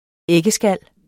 Udtale [ ˈεgəˌsgalˀ ]